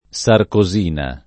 sarcosina [ S arko @& na ] s. f. (chim.)